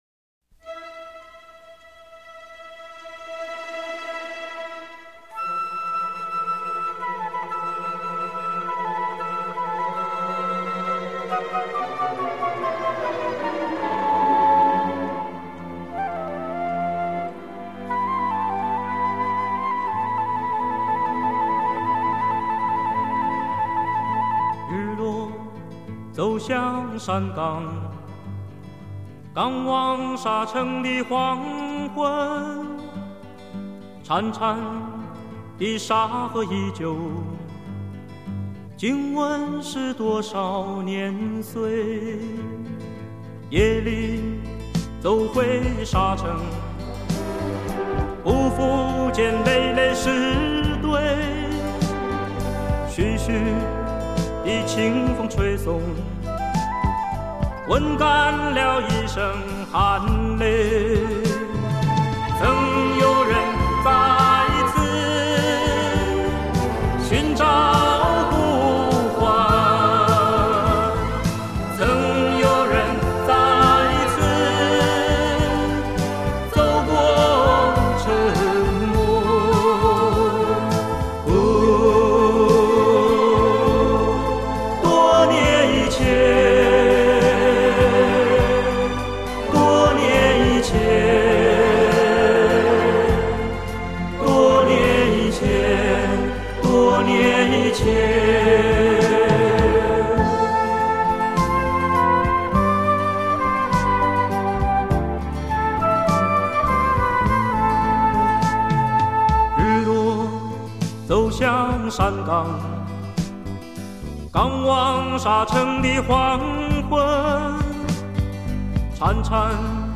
二重唱